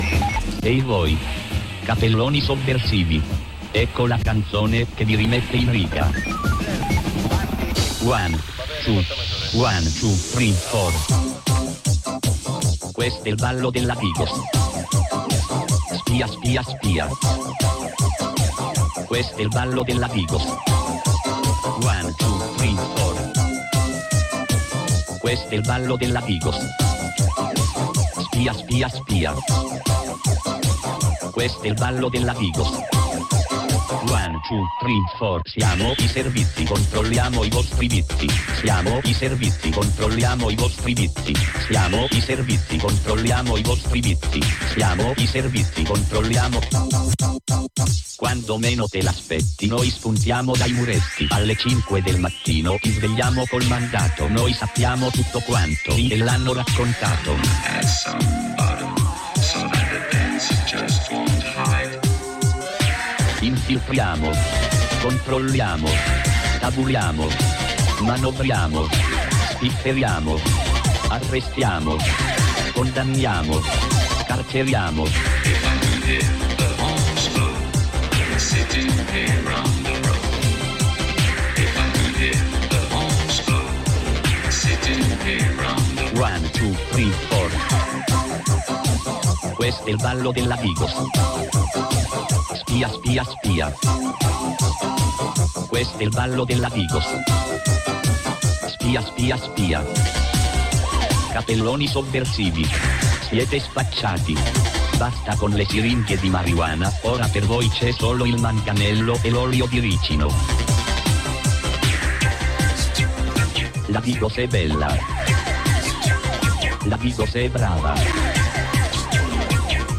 Dalla voce di una protagonista del mondo dei sex workers, pensieri critici sul sesso e sulla libertà – 14.10.2022